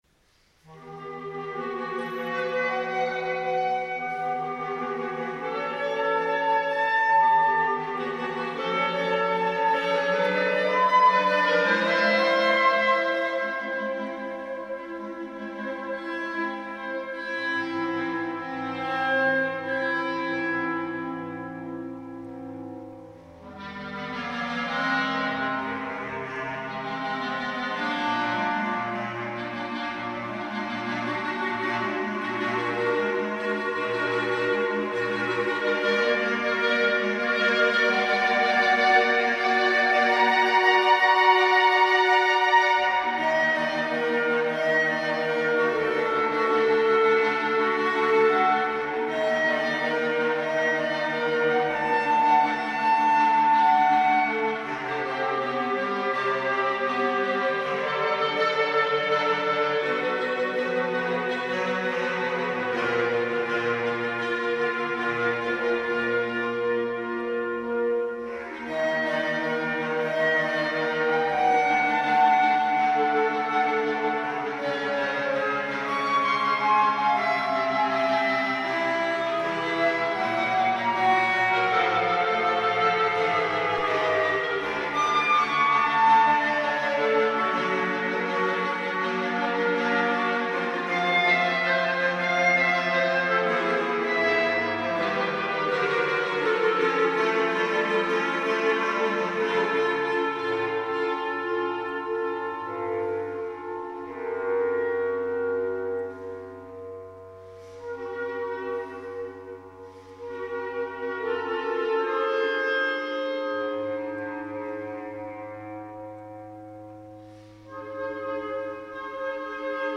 « Dedalus» è un brano fortemente ritmico e basato su note ribattute in modo quasi ossessivo, con incisi brevi e pungenti. La sezione contrastante è più cantabile, ma sempre pervasa dal ribattuto incessante, quasi un simbolo dello smarrimento nel labirinto.
DEDALUS (2004 version) for clarinet quartet (per quartetto di clarinetti) [duration about 7 minutes] Performed by the Quartetto Manfroce (Palmi - Italy).